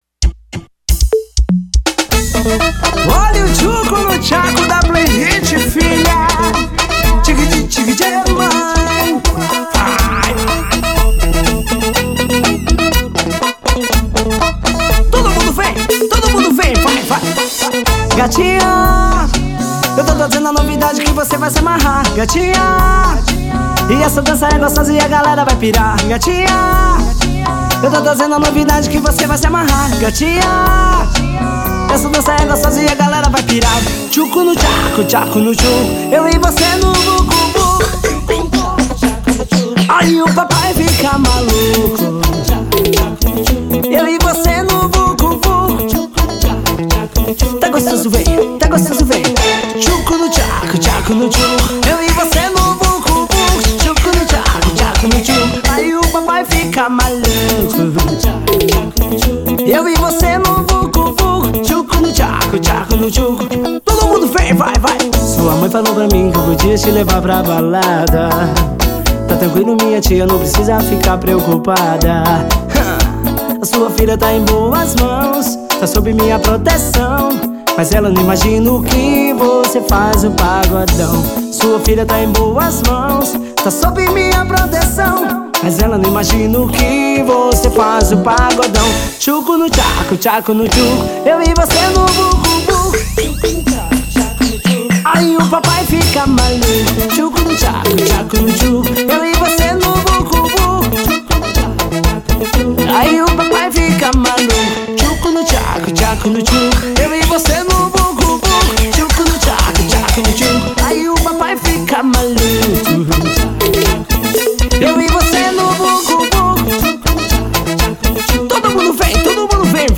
Pagodão.